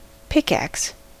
Ääntäminen
IPA : /ˈpɪkˌæks/